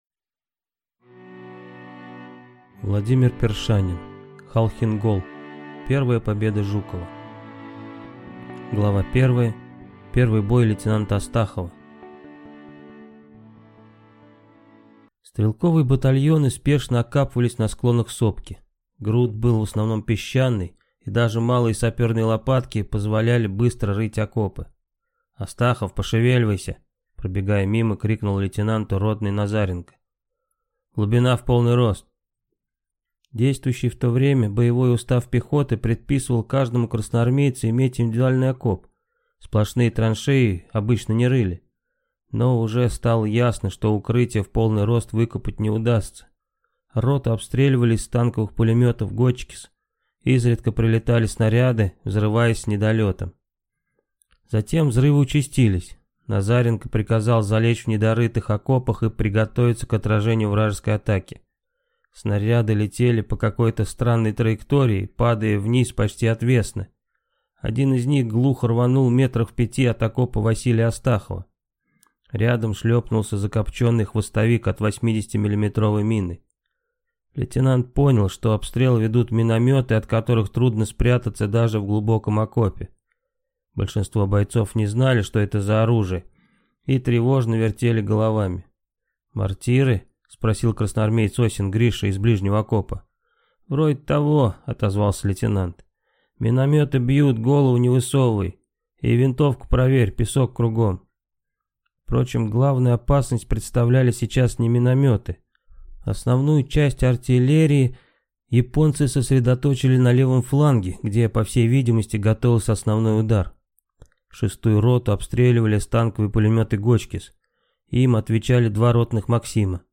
Аудиокнига Халхин-Гол. Первая победа Жукова | Библиотека аудиокниг